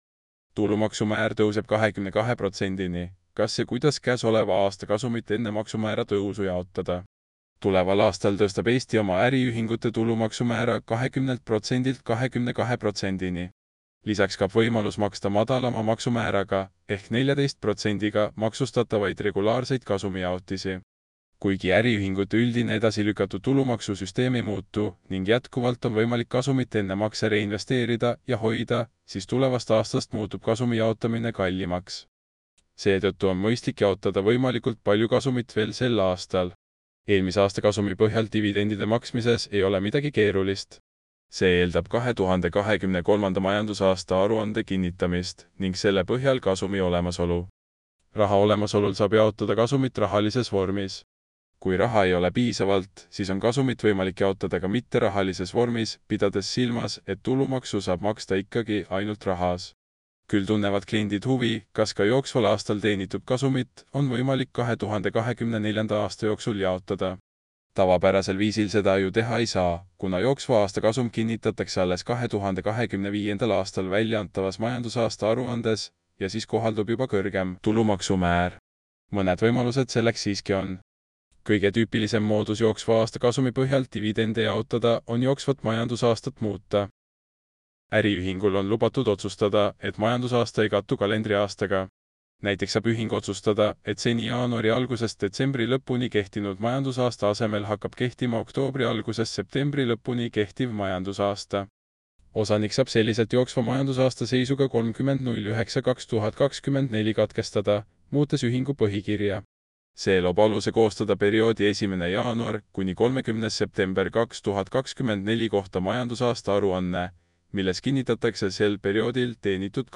Kui silmad puhkust vajavad, anna artikkel üle kõnerobotile – vajuta ja kuula!